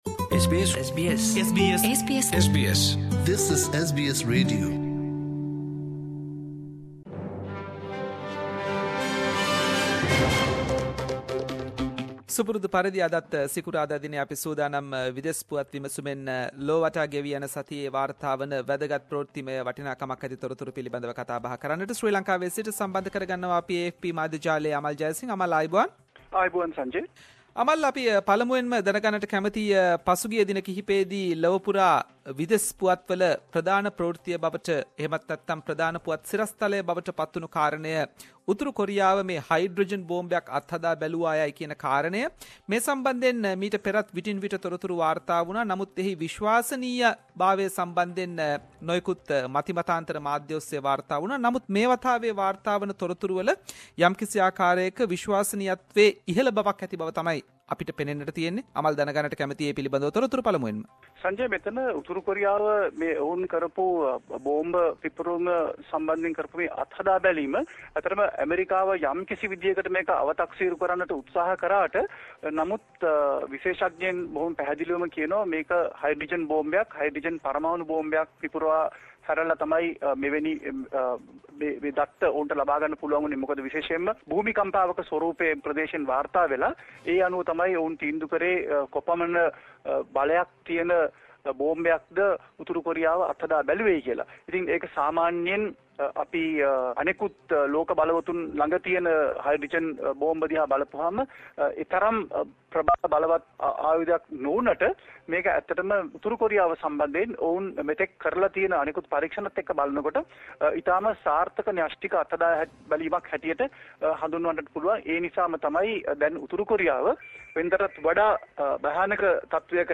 “SBS Sinhala” Around the World – Weekly World News highlights…..